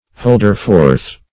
Search Result for " holder-forth" : The Collaborative International Dictionary of English v.0.48: Holder-forth \Hold"er-forth`\, n. One who speaks in public; an haranguer; a preacher.